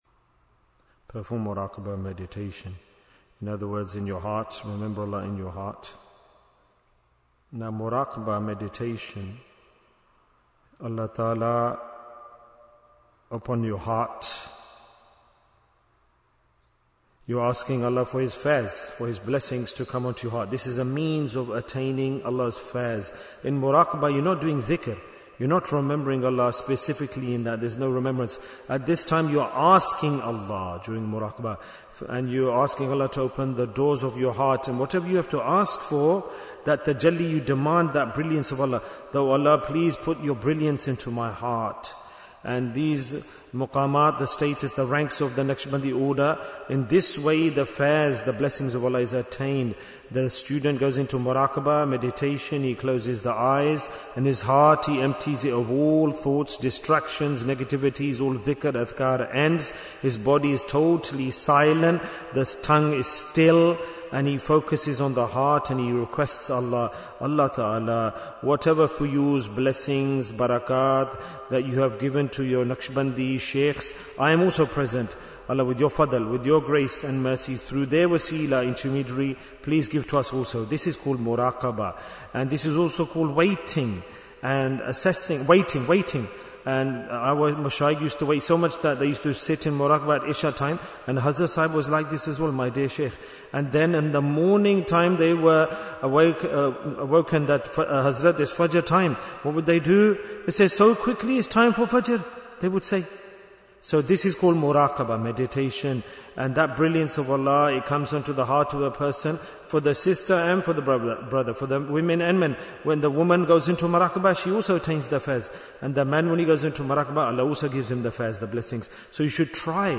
Jewels of Ramadhan 2026 - Episode 40 Bayan, 44 minutes19th March, 2026